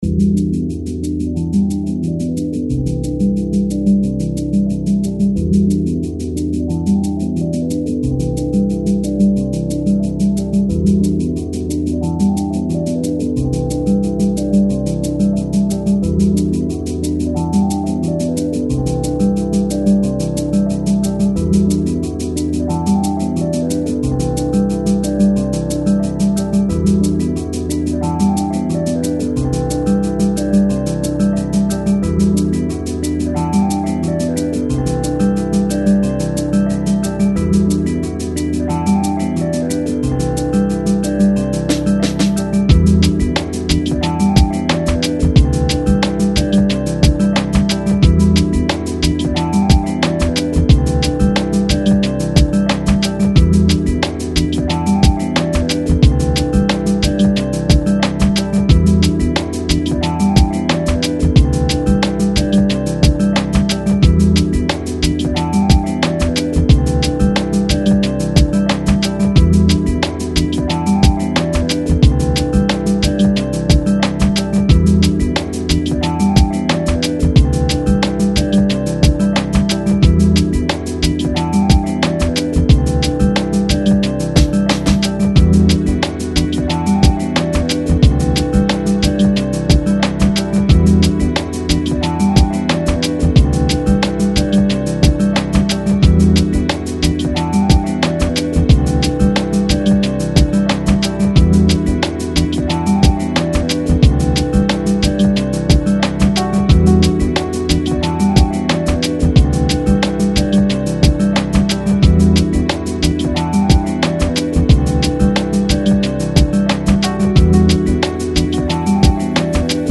Lo-Fi, Lounge, Chillout Год издания